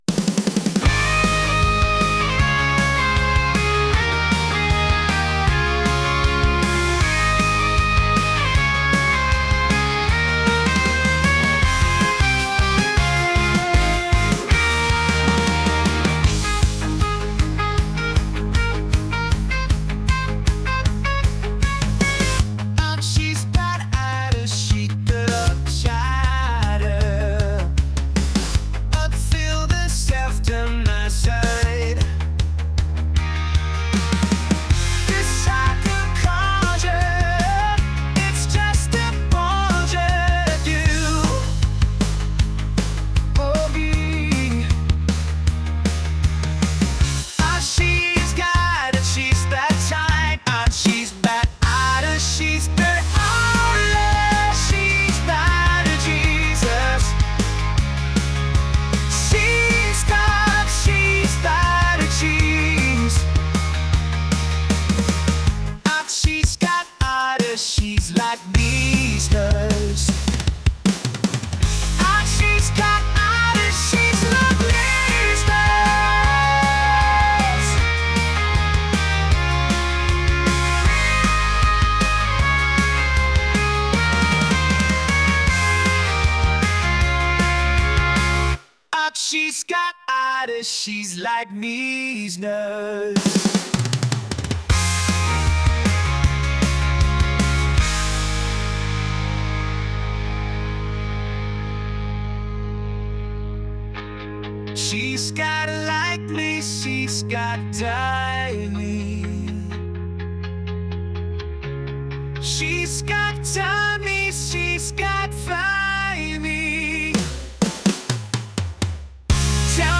pop | rock | energetic